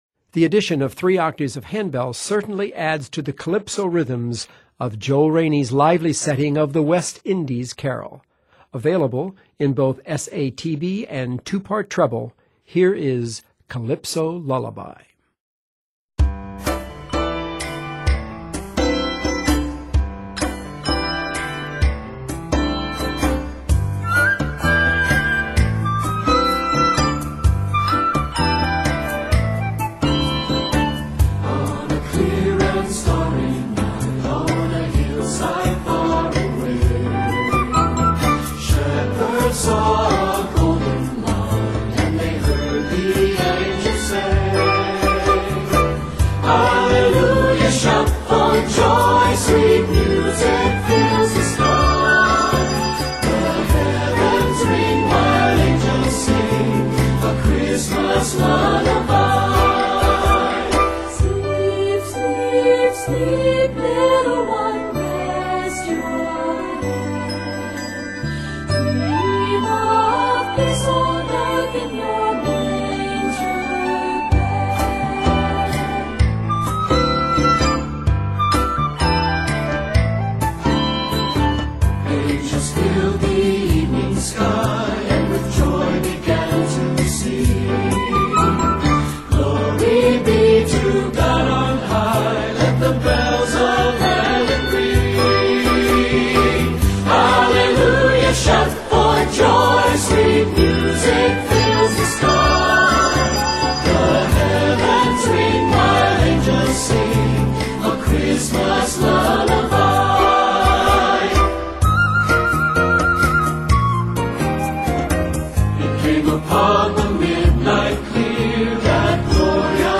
Set in G Major and Ab Major, this work is 93 measures.